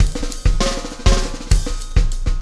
Extra Terrestrial Beat 07.wav